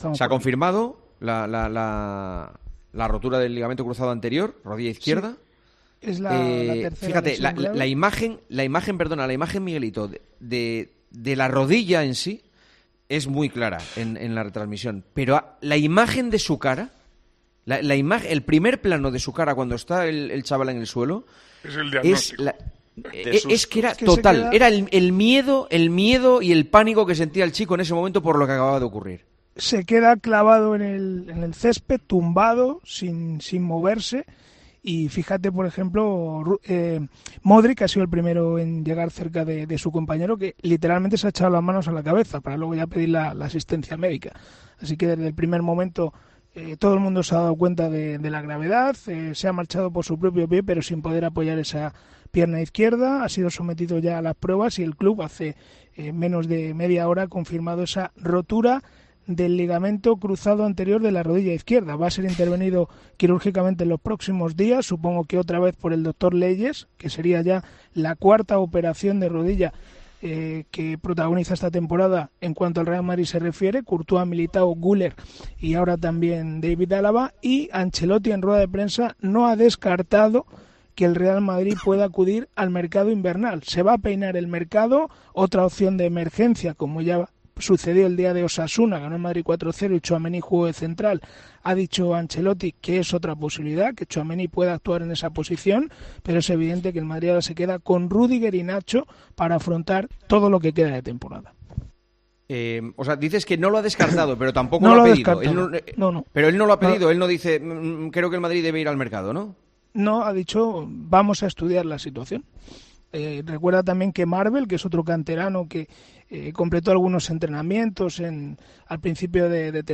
En el TertuliónTJCOPE hemos debatido sobre qué debe hacer el conjunto blanco en esta posición.
Con Paco González, Manolo Lama y Juanma Castaño